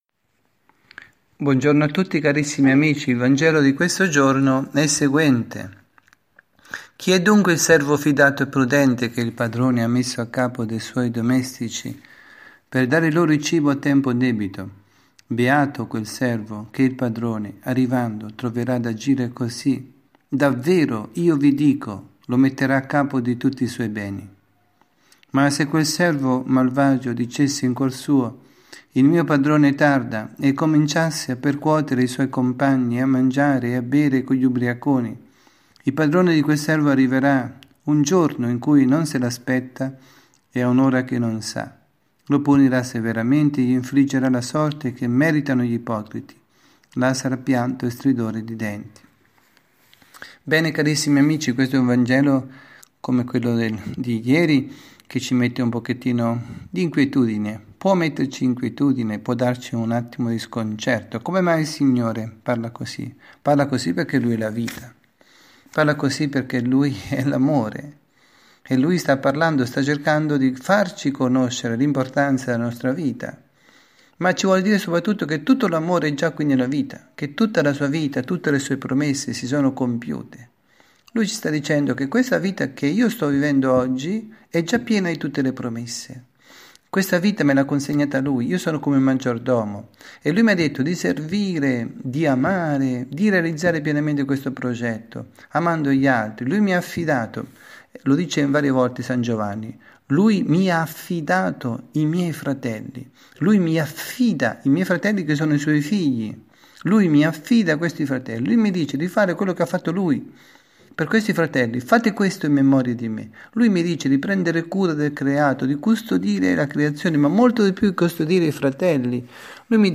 dalla Parrocchia S. Rita, Milano